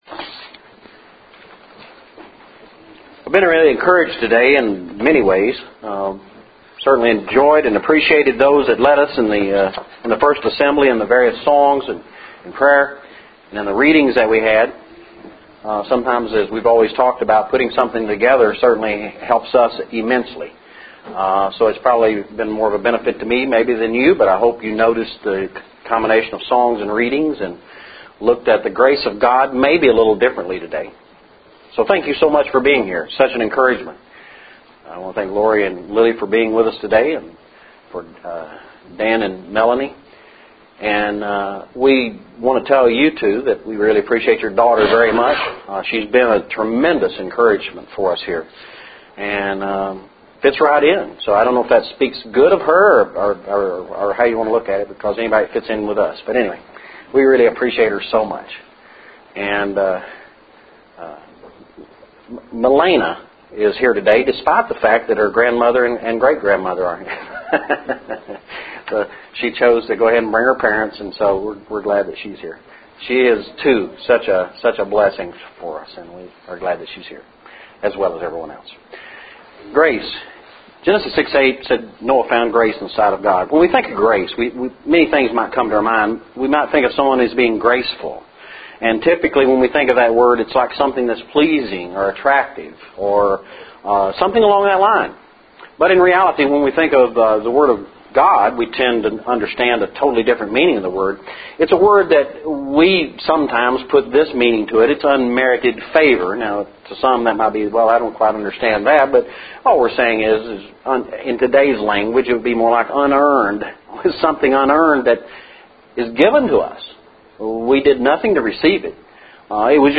Grace Lesson – 01/29/12 – Waynesville Church of Christ
Grace Lesson – 01/29/12